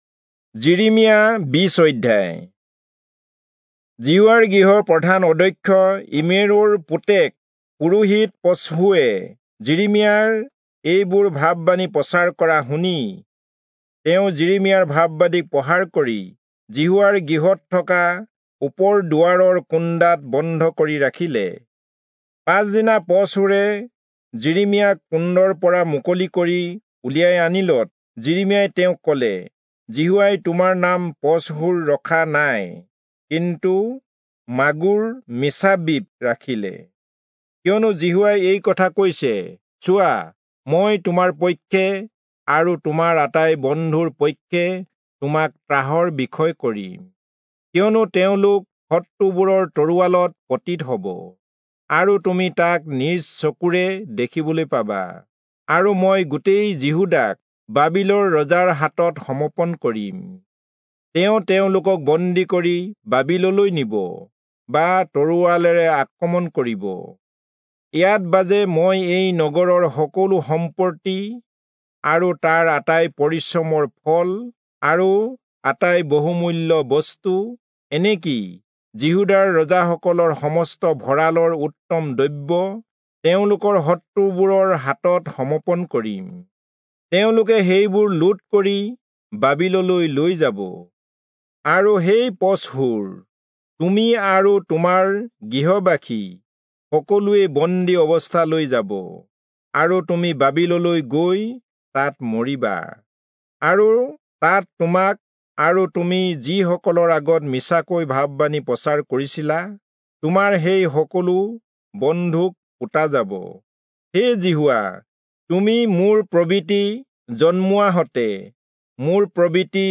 Assamese Audio Bible - Jeremiah 35 in Rv bible version